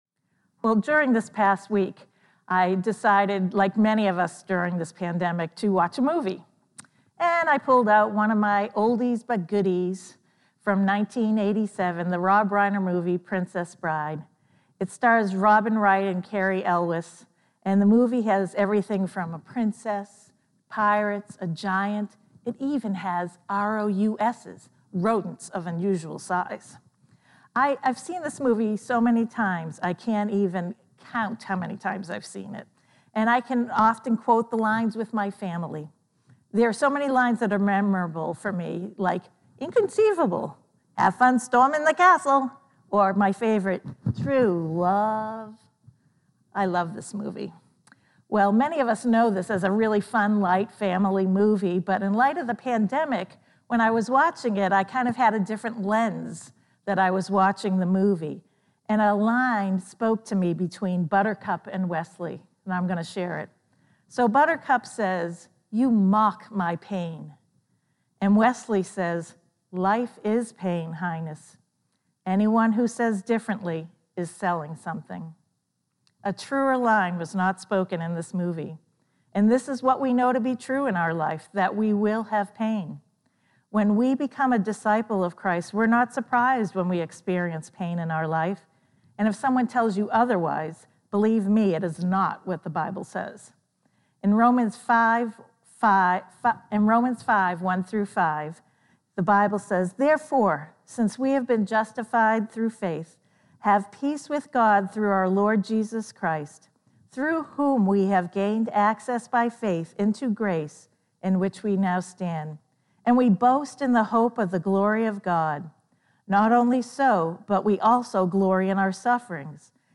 The first video below is JUST THE SERMON.